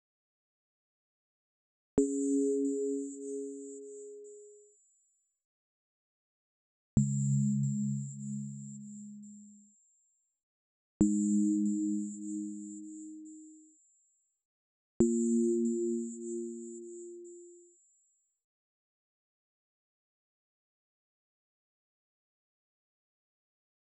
tones.wav